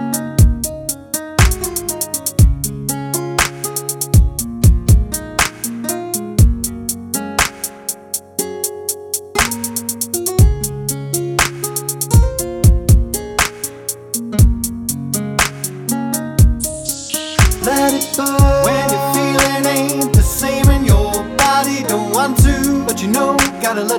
no Backing Vocals R'n'B